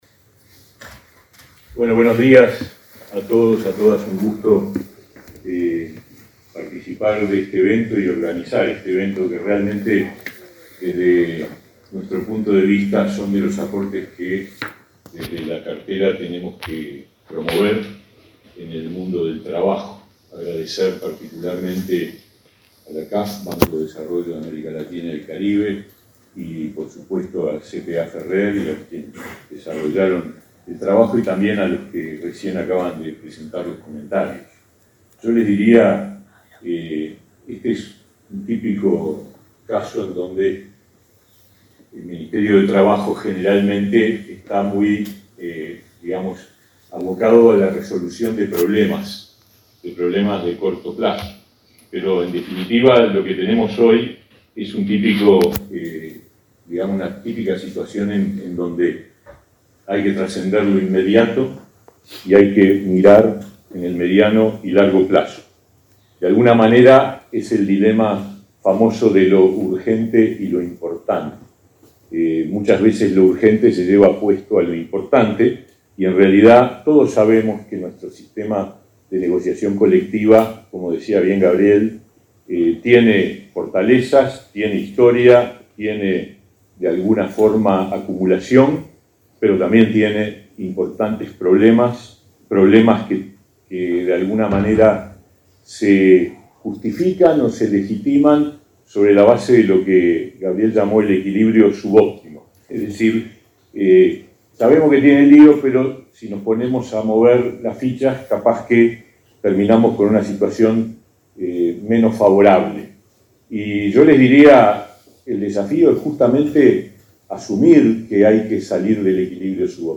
Palabras del ministro de Trabajo, Pablo Mieres
Este martes 10, en Montevideo, el ministro de Trabajo, Pablo Mieres, participó en la presentación de un informe realizado por la consultora CPA